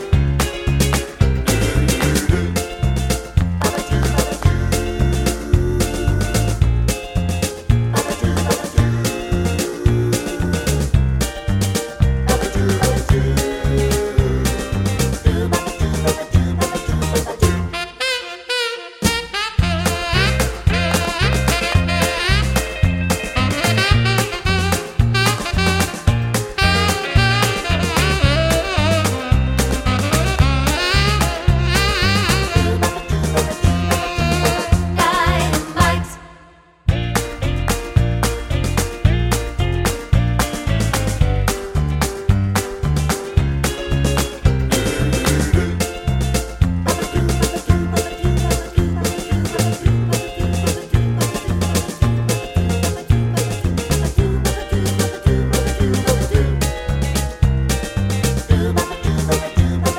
no sax solo Pop (1960s) 2:05 Buy £1.50